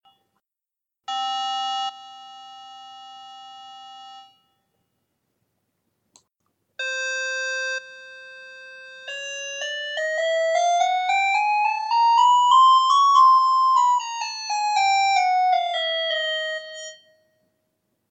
With either my Yeti Blue, or my Logitech webcam (both of which connect thru USB ports), a constant pitch sound maintains volume for about 1 sec and then falls off dramatically, as recorded on Audacity. If the frequency changes it returns to proper volume but only for another second.
Attached is a recording of two experiments, the first, a constant pitch, the second, starting with one pitch, running thru several pitches and then stopping on a pitch.